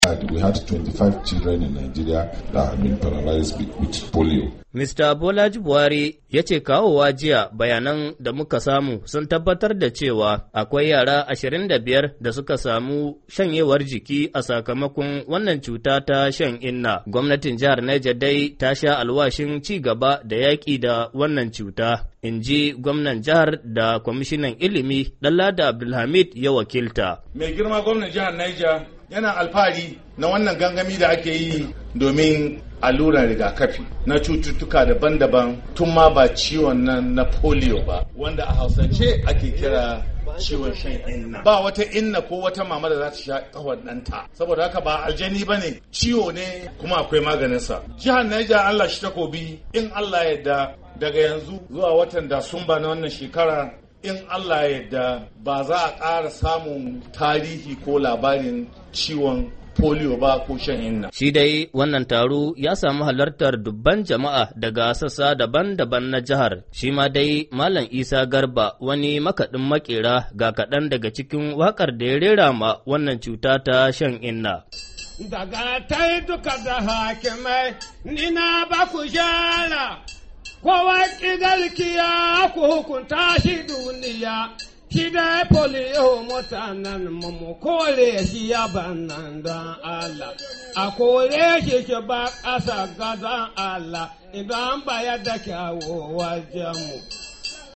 Rahoton Rigakafin Shan Inna A Jihar Naija - 1:37